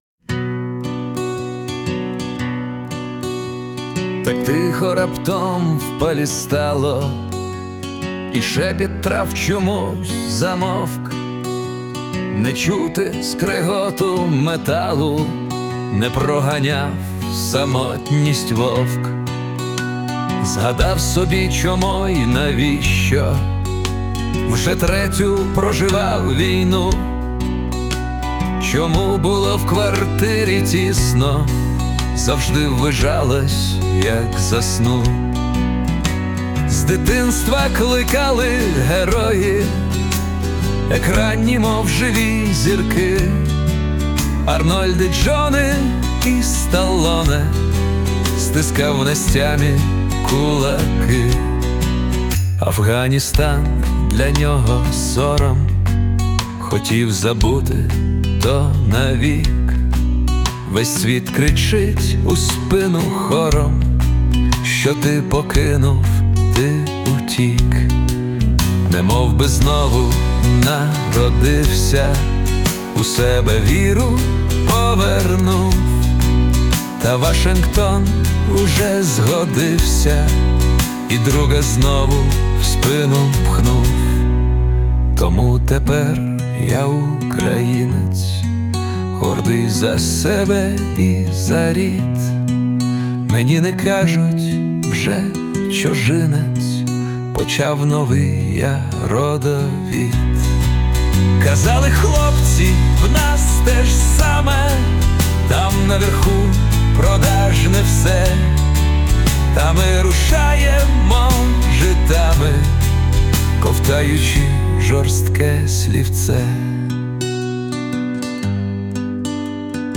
Кавер
12 Пісня на часі. hi friends